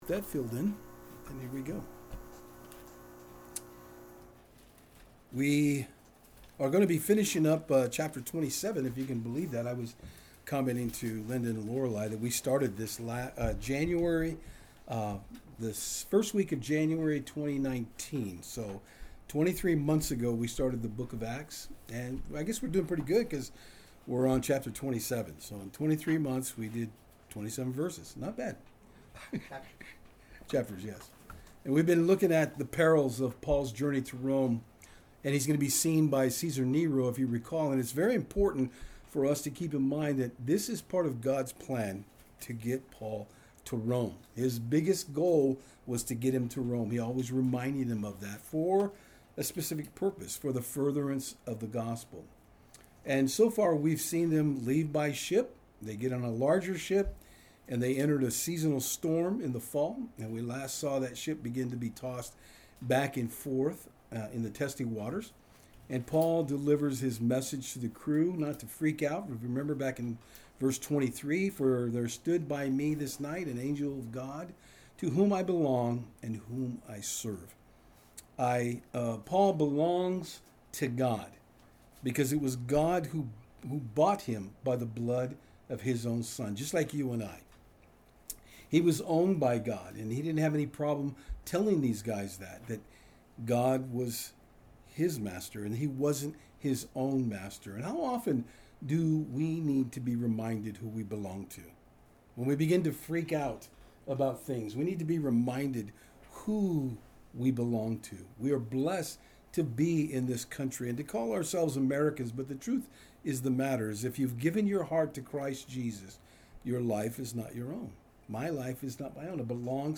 Service Type: Saturdays on Fort Hill